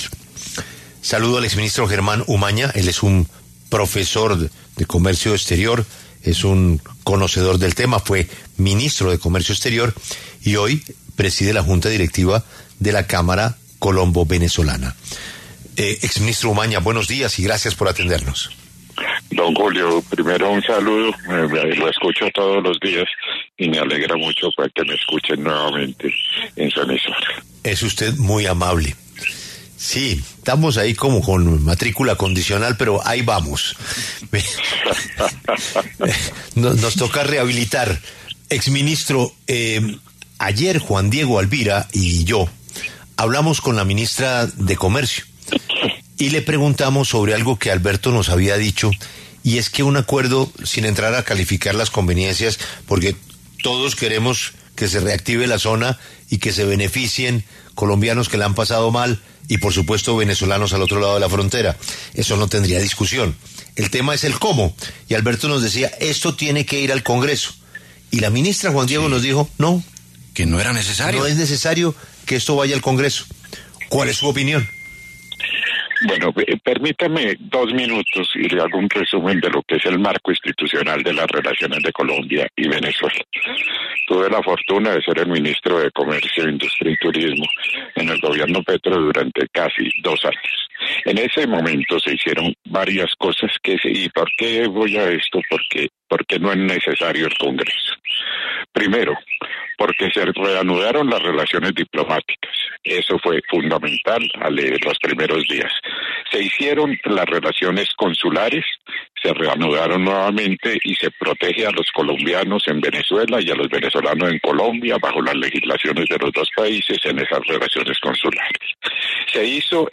Germán Umaña, presidente de la junta directiva de la Cámara Colombo-Venezolana, habló en La W sobre el memorando de entendimiento de la zona económica binacional firmado entre ambos países.